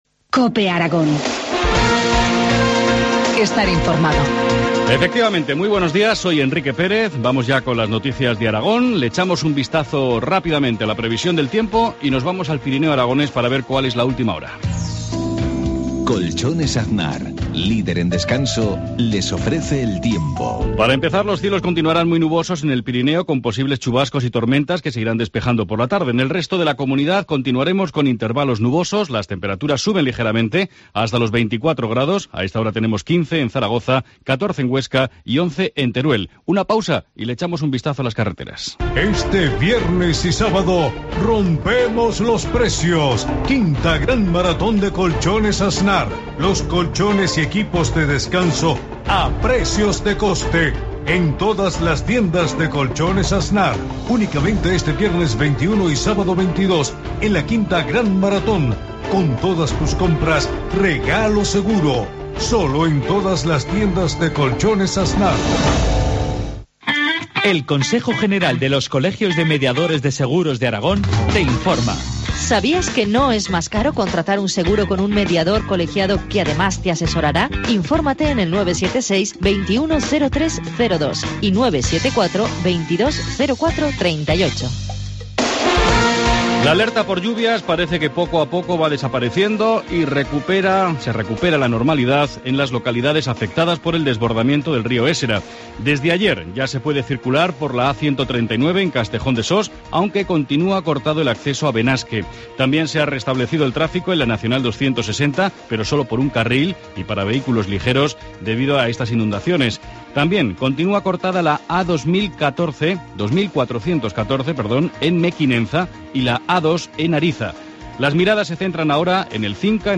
Informativo matinal, jueves 20 de junio, 7.53 horas